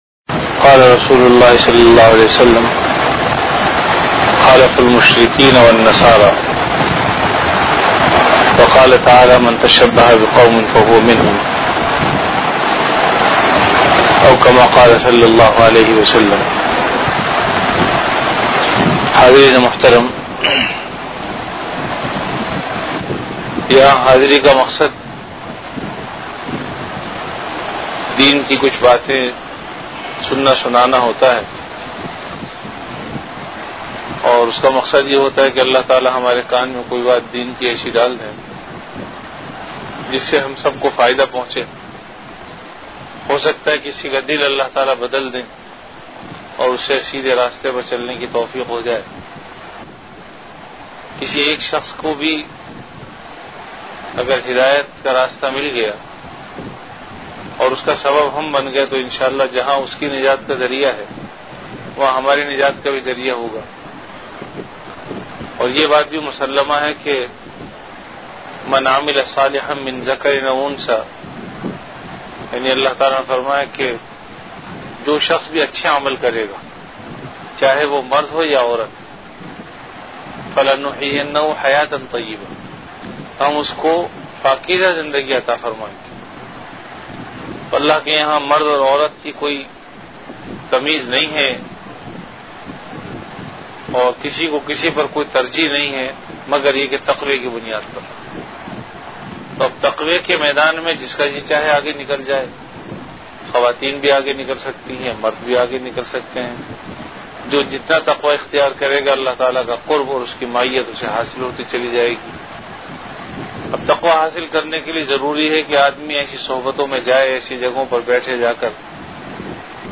Bayanat · Jamia Masjid Bait-ul-Mukkaram, Karachi